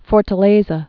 (fôrtl-āzə, -tə-lĕ-)